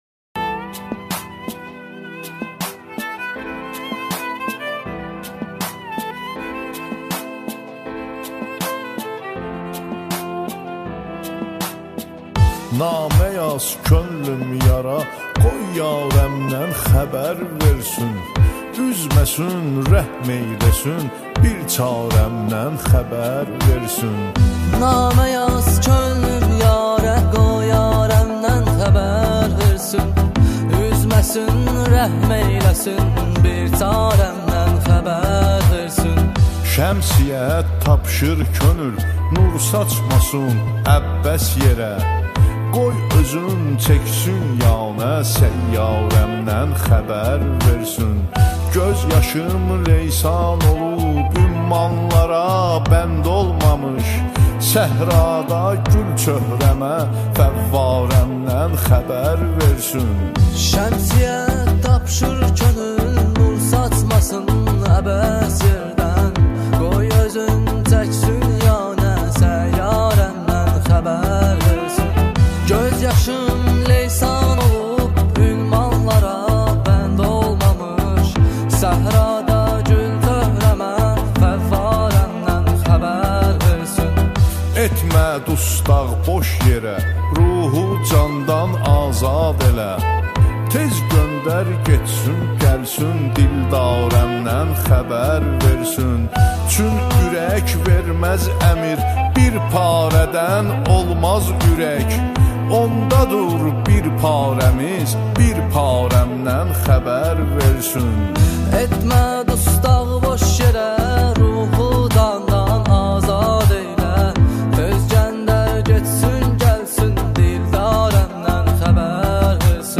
• Жанр: Турецкая музыка